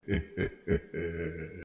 阿蒙顿的笑声
描述：阿马格登项目的低音笑声
Tag: 150 bpm Hip Hop Loops Fx Loops 275.80 KB wav Key : Unknown